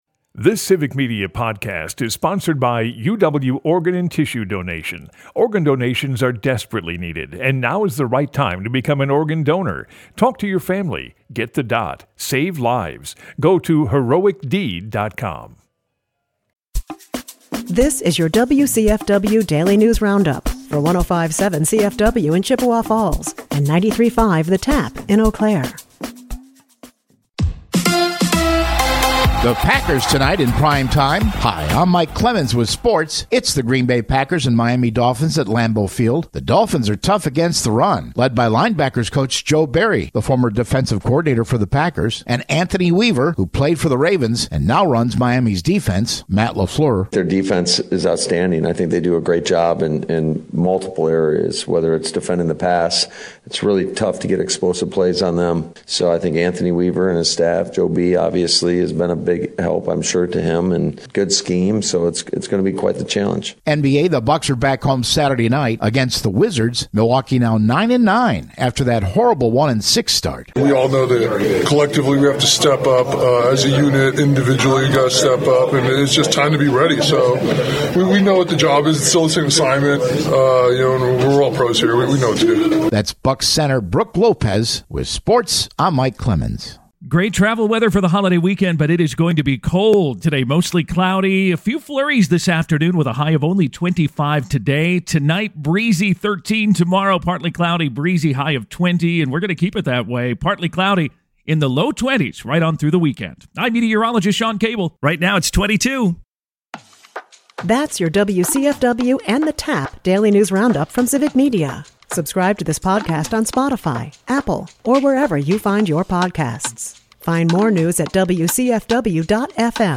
wcfw news